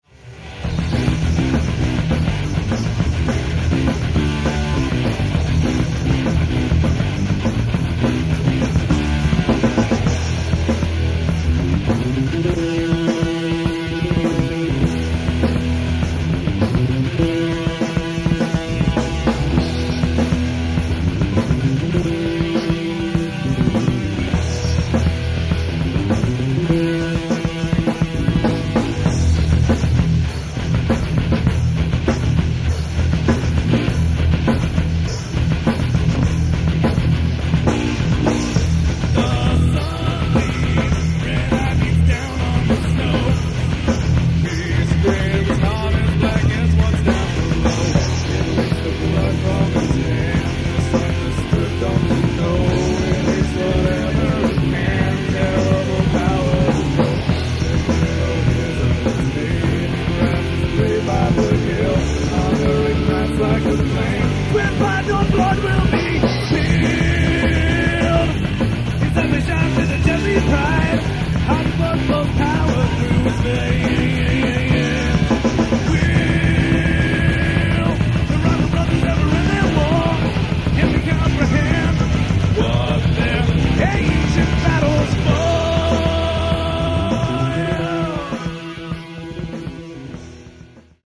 Founded around 1992 we were a pretty succesful rock-/metal-band in the White Plains-Scarsdale area until we all moved off to various places of the world to go to college.
vocals
guitars
drums
bass